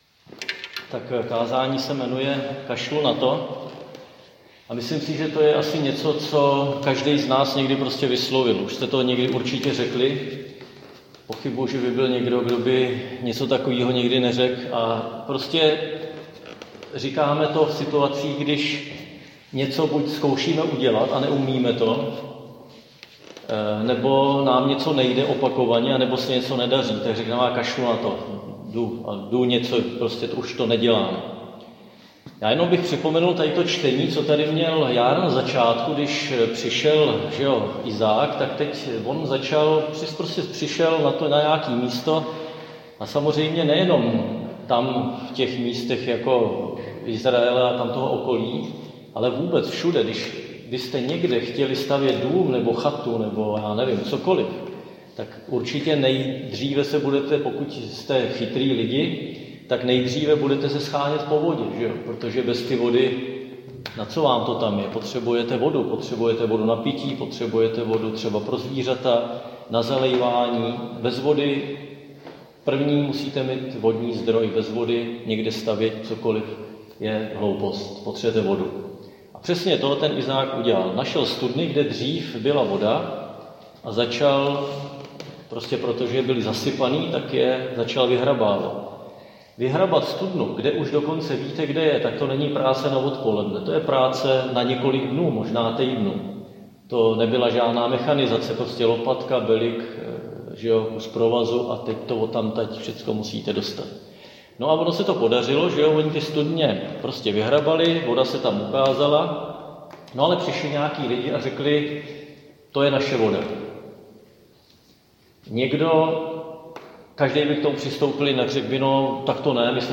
Křesťanské společenství Jičín - Kázání